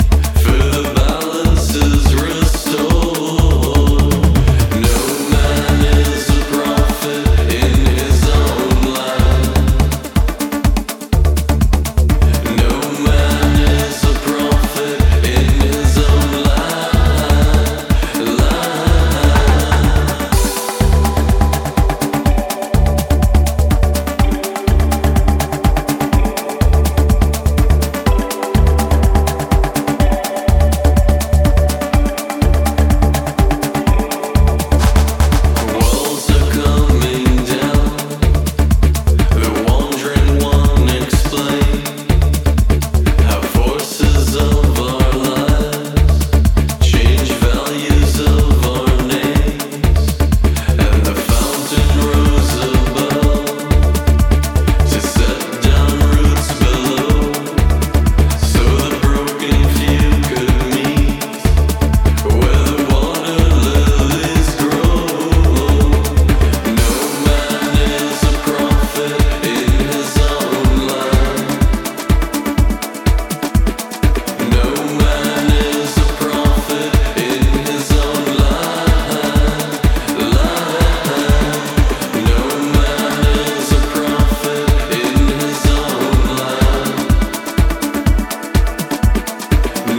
thoughtful artrock and new wave aesthetic
including his portentous vocal hook ?
hypnotic groove, fat low-end and a ton of percussion